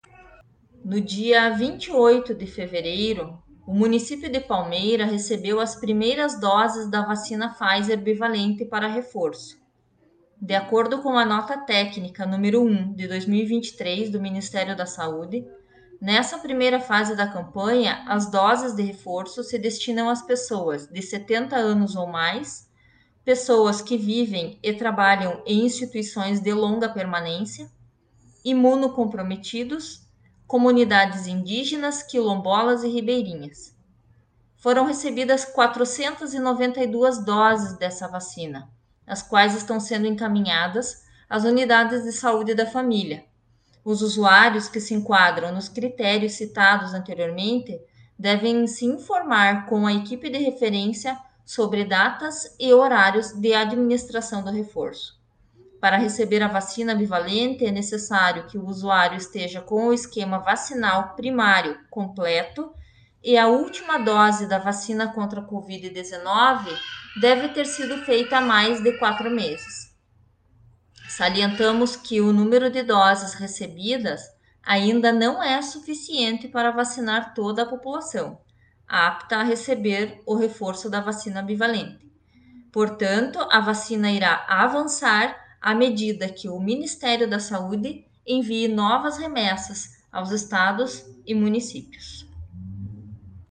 A secretária de Saúde, Ana Paula Ferreira Marques, fala sobre como vai acontecer a campanha de vacinação no município, que, a princípio, vai atender os idosos com mais de 70 anos, quem vive ou trabalha em instituições de longa permanência, imunocomprometidos, indígenas, quilombolas e ribeirinhos.